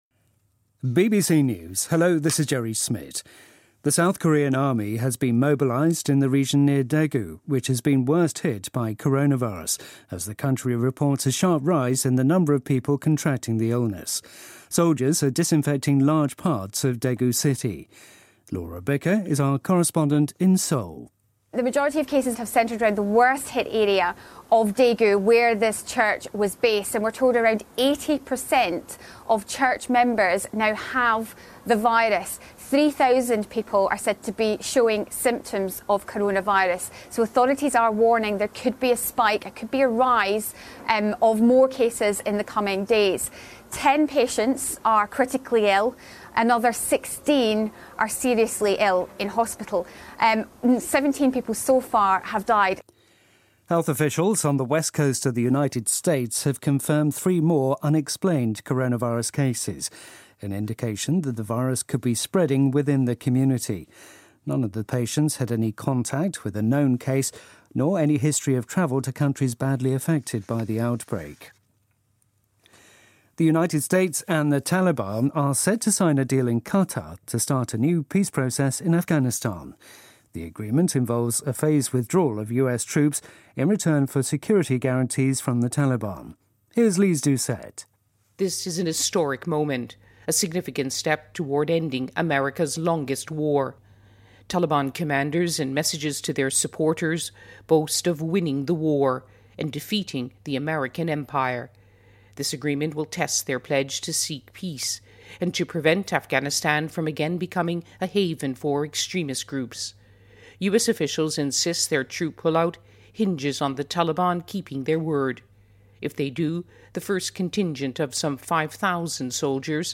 News
英音听力讲解:美国和阿富汗塔利班签署和平协议